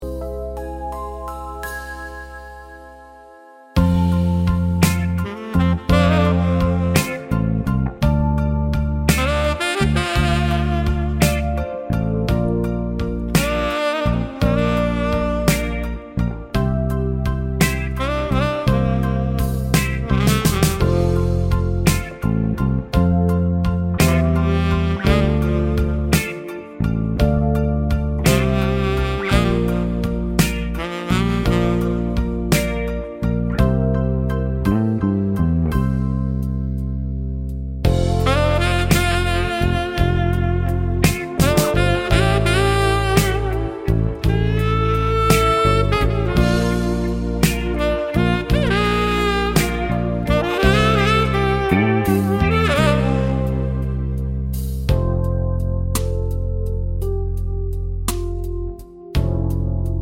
no Backing Vocals Country (Male) 4:14 Buy £1.50